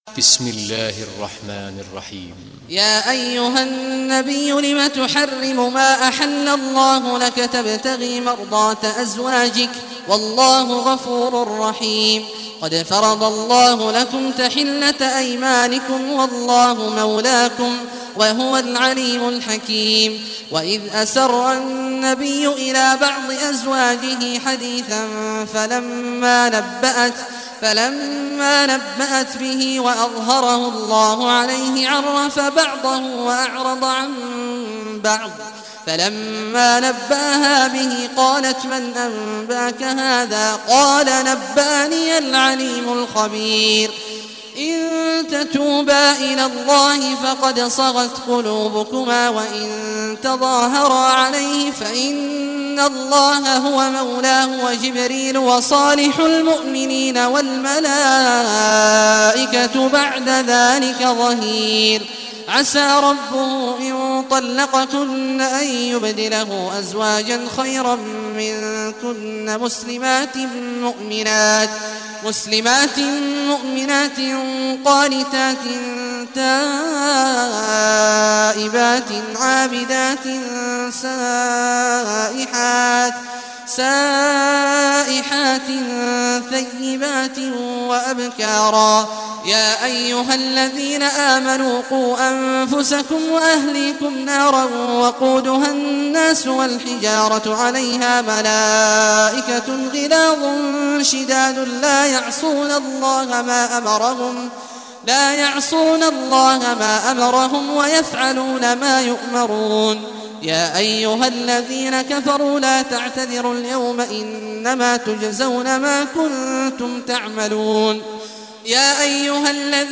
Surah At-Tahrim Recitation by Sheikh Al Juhany
Surah At-Tahrim, listen or play online mp3 tilawat / recitation in Arabic in the beautiful voice of Imam Sheikh Abdullah Awad al Juhany.